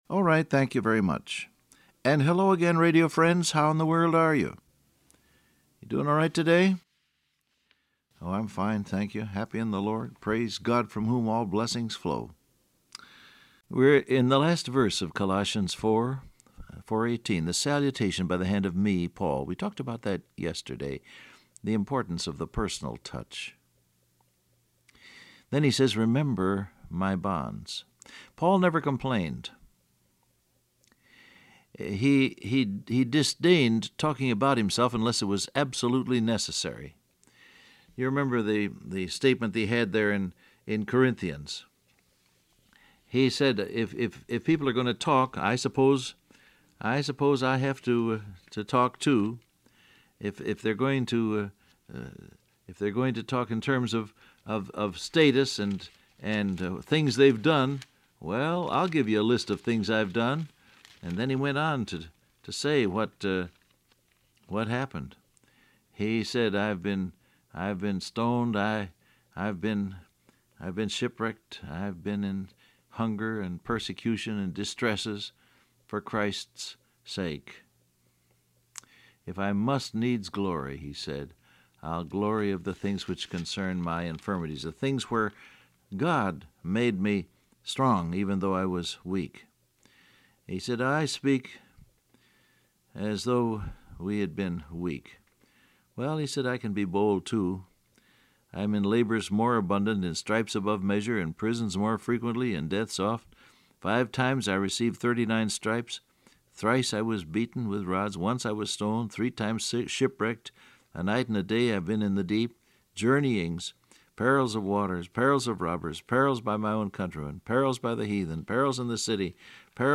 Download Audio Print Broadcast #6295 Scripture: Colossians 4:18 , Ephesians 6:19 Topics: Troubles , Prevailing Prayer , Complaining Transcript Facebook Twitter WhatsApp Alright, thank you very much.